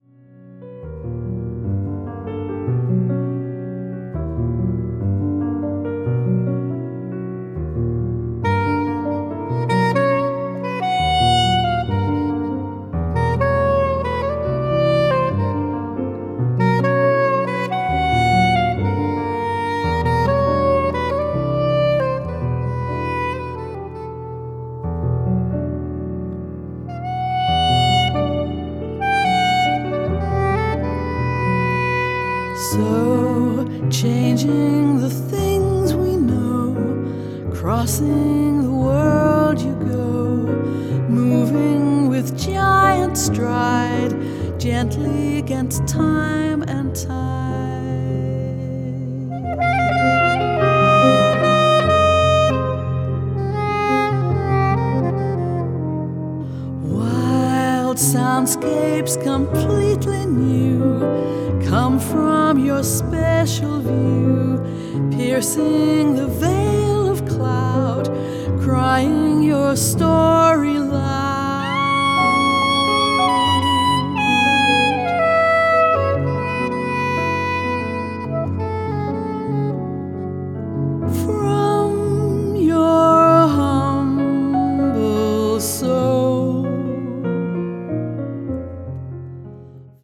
piano
contemporary jazz   deep jazz   jazz vocal